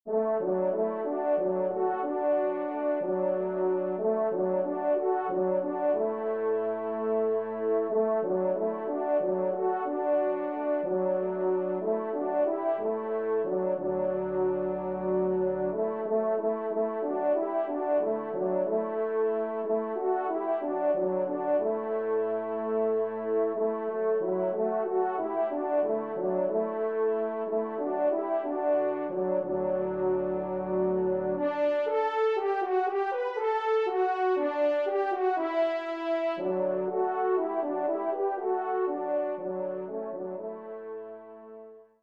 Pupitre 2° Cor (en exergue)